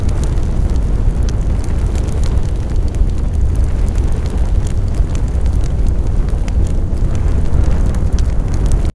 Fire-Blaze.ogg